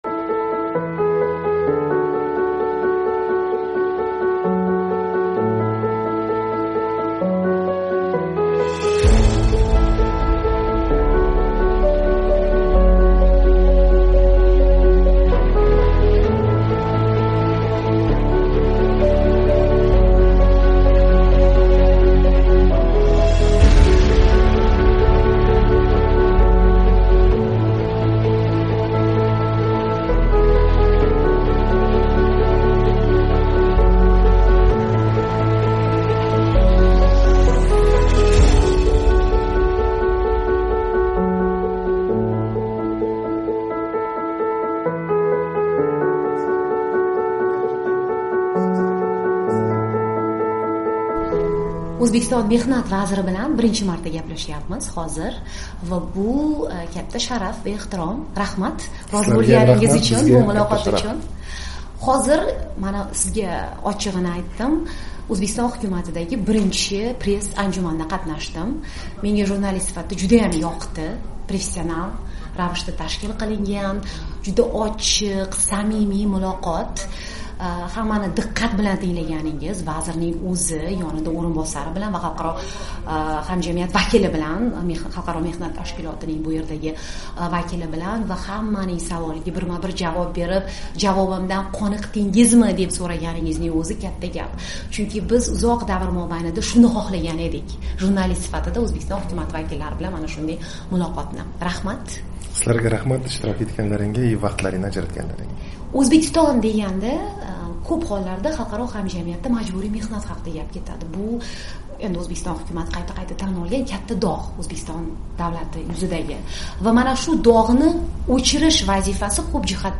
O'zbekiston Bandlik va mehnat munosabatilari vaziri bilan suhbat, Toshkent
O'zbekistonda paxta terimi boshlanar ekan, Bandlik va mehnat munosabatlari vaziri Sherzod Kudbiyev "Amerika Ovozi" bilan suhbatda bo'ldi. Hukumat majburiy mehnat masalasini bartaraf etishga bel bog'lagan, jiddiy harakat ketmoqda, deydi u yuzma-yuz intervyuda.